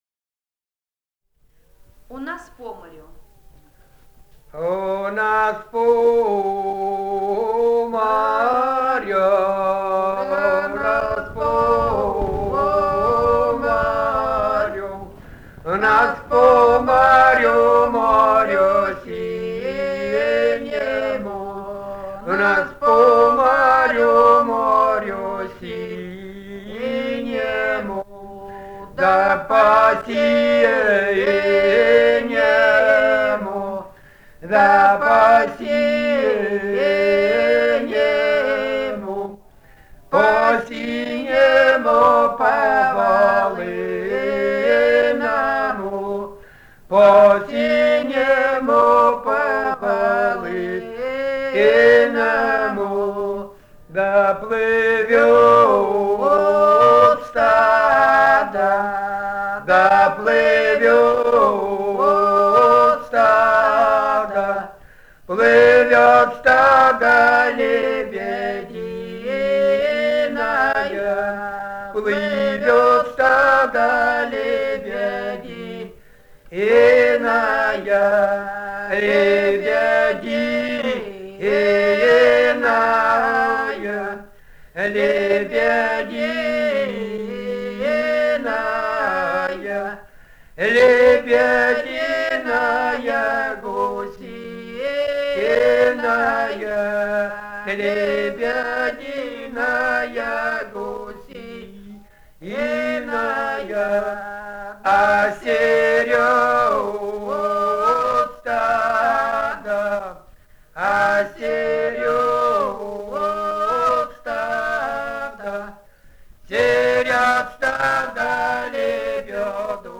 Этномузыкологические исследования и полевые материалы
«У нас по морю» (хороводная).
Алтайский край, с. Михайловка Усть-Калманского района, 1967 г. И1001-04